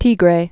(tēgrā, -grĕ)